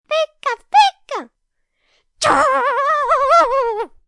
Download Free Pikachu Sound Effects